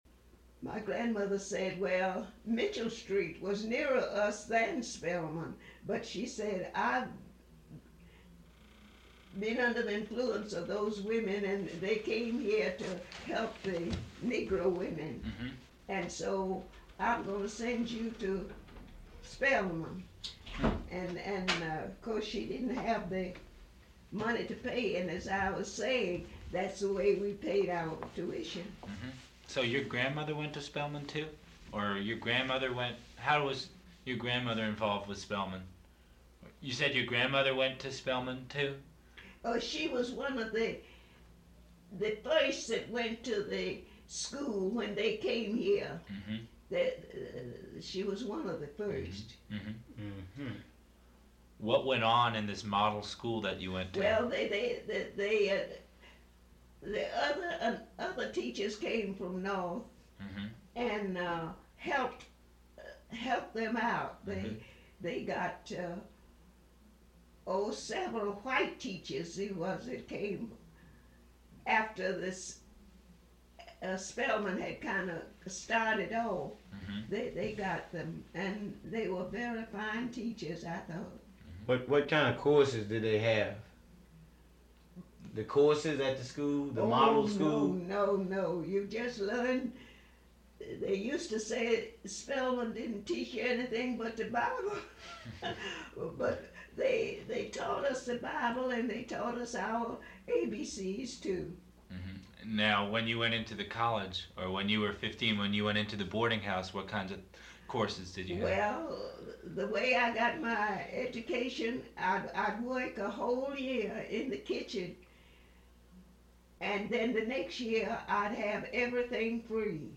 Radio Free Georgia has even partnered with Atlanta History Center to record a fascinating series of oral histories that detail the experiences of Atlantans great and small between the first and second World Wars.